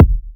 edm-kick-87.wav